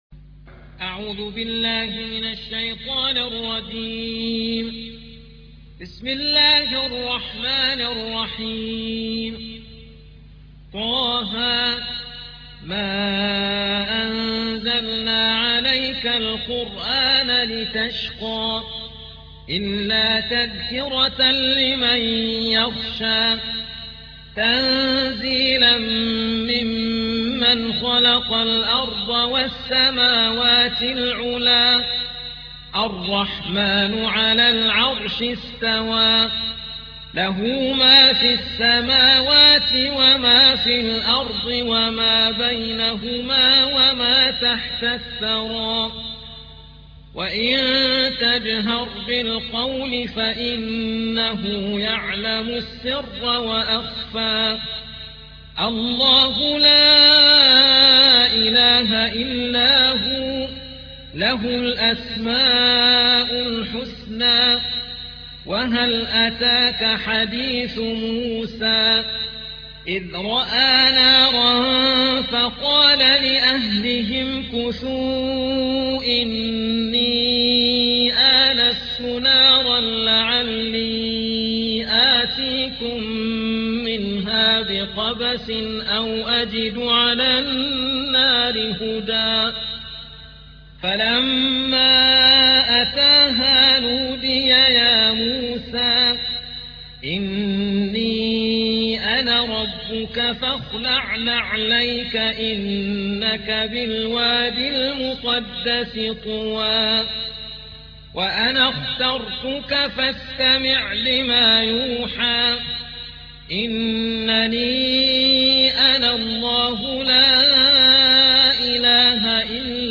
تحميل : 20. سورة طه / القارئ عبد الهادي كناكري / القرآن الكريم / موقع يا حسين